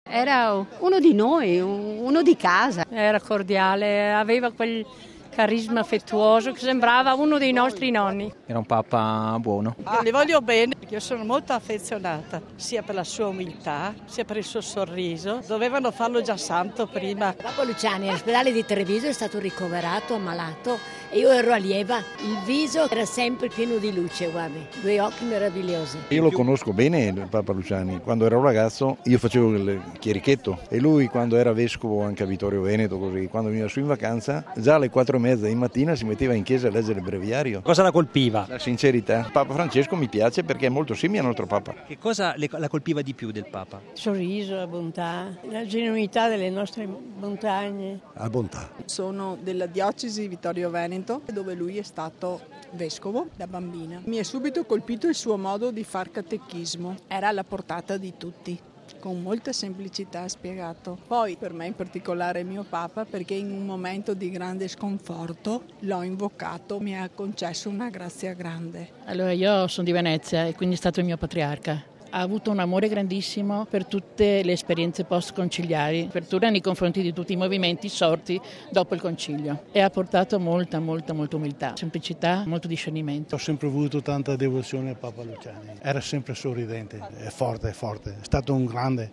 In centinaia sono giunti a Canale D'Agordo, nel cuore delle Dolomiti, per manifestare il proprio affetto a Giovanni Paolo I. Ascoltiamo alcune testimonianze: